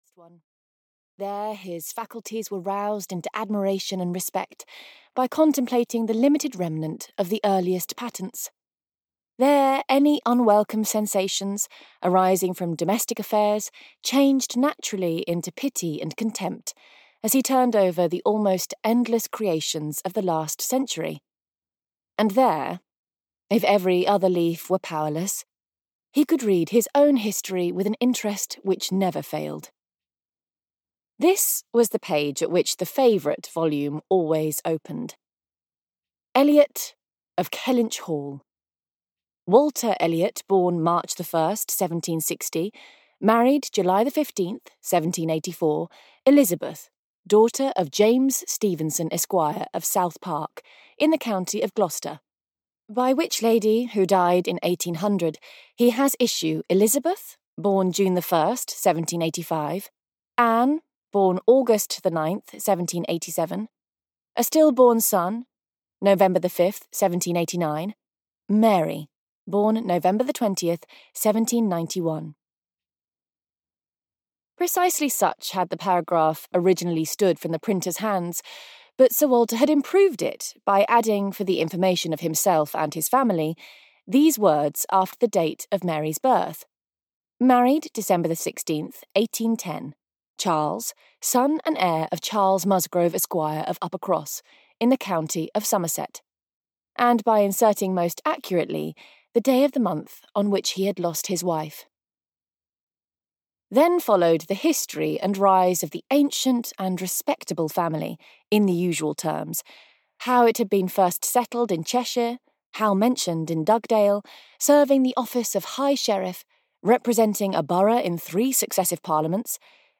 Persuasion (EN) audiokniha
Ukázka z knihy